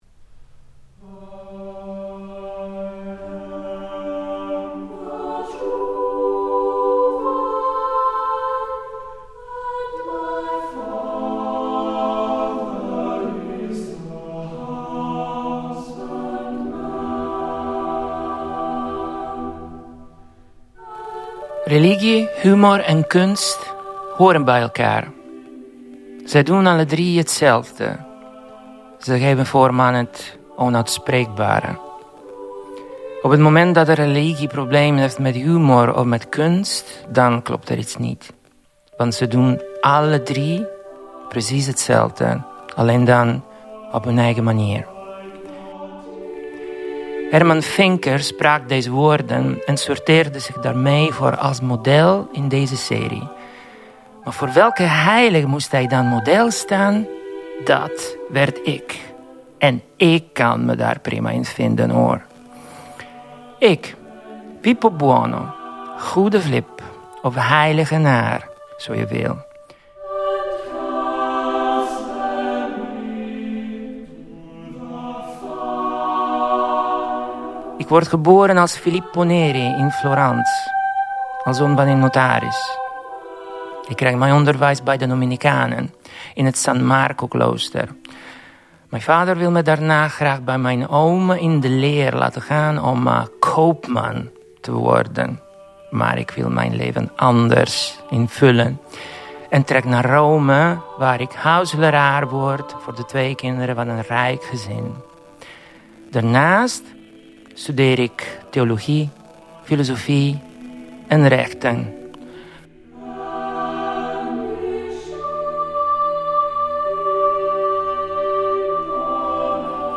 Ho avuto il piacere di recitare il santo italiano Filippo Neri. Ecco il risultato: S. Filippo Neri